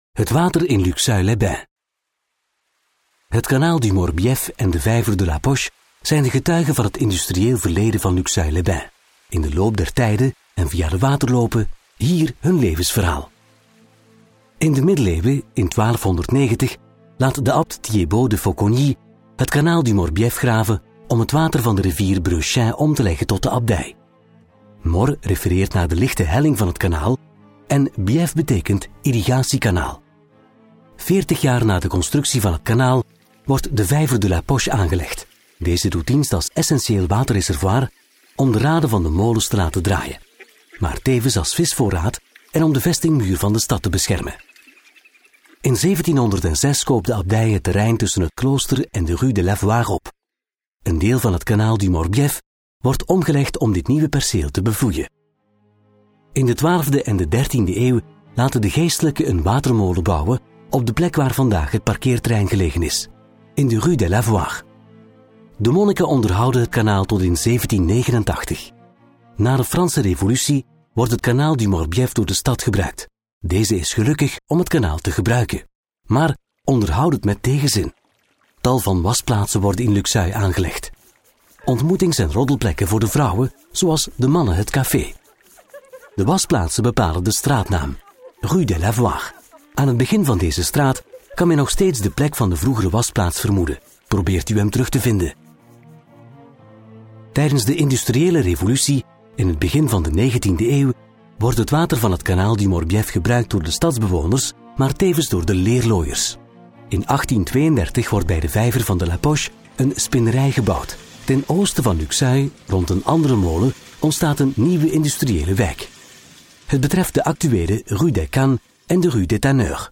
Explications audio